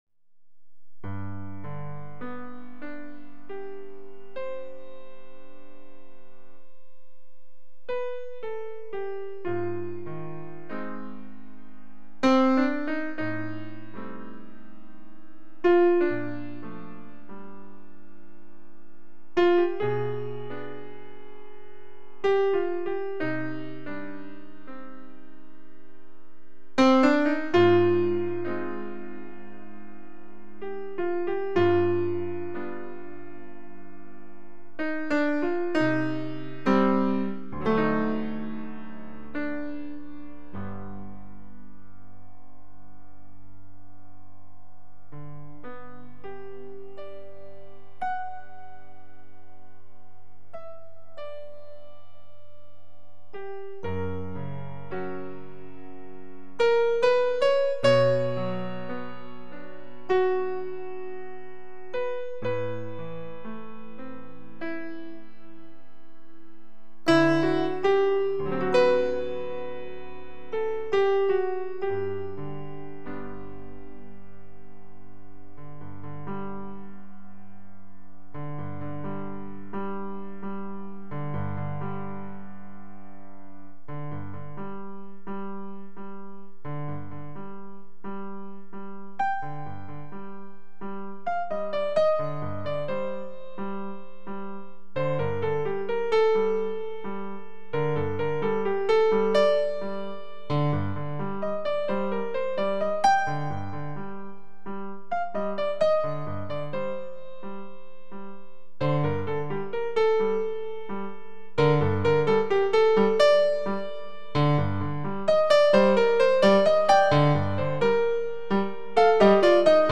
The pieces in this collection are among my first real attempts at composing music for synthesizers (mainly a Korg M1 and a Yamaha DX7).
I still don't know what they're about, but now I feel that there is a certain cinematic aspect to some of them.